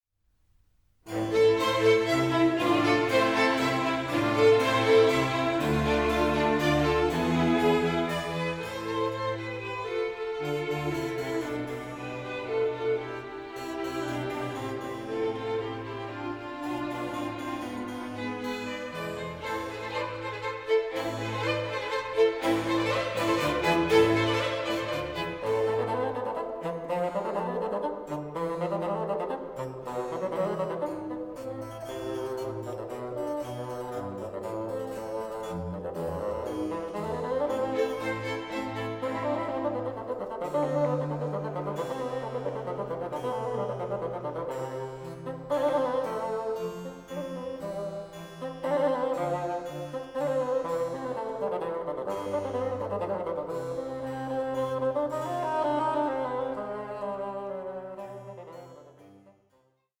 Bassoon Concerto in F